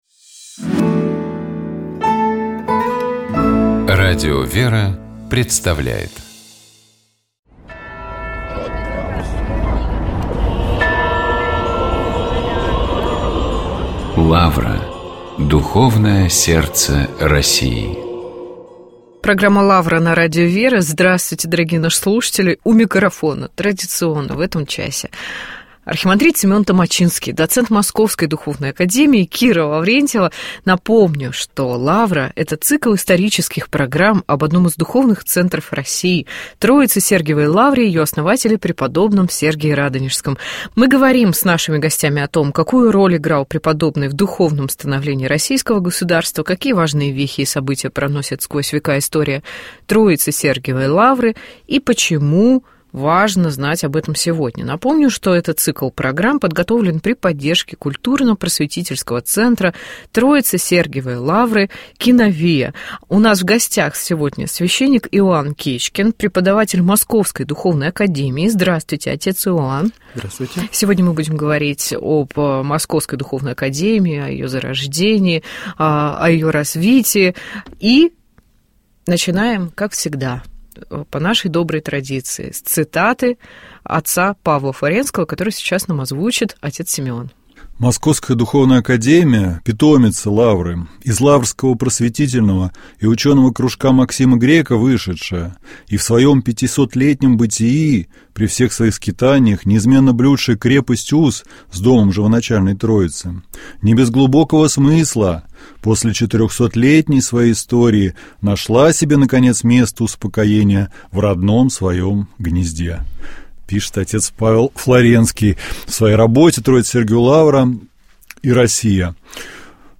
Каждую пятницу ведущие, друзья и сотрудники радиостанции обсуждают темы, которые показались особенно интересными, важными или волнующими на прошедшей неделе.